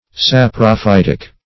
Saprophytic \Sap`ro*phyt"ic\, a.